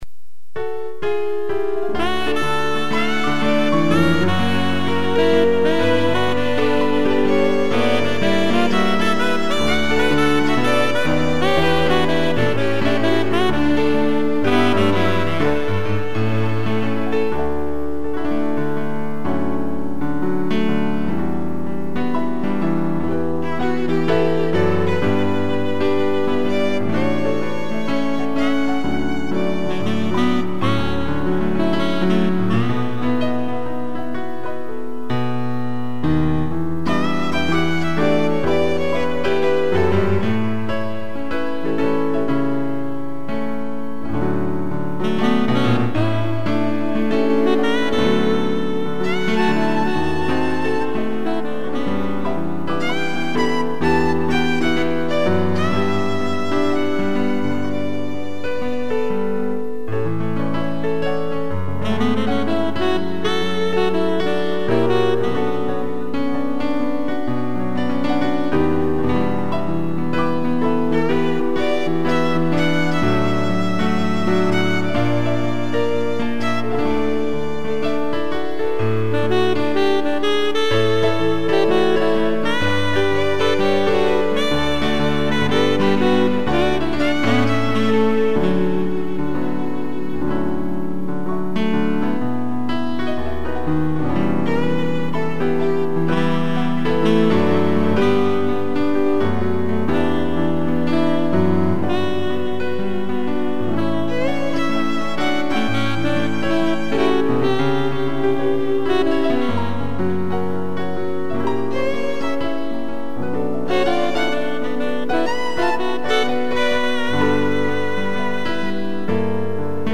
2 pianos, sax e violino
(instrumental)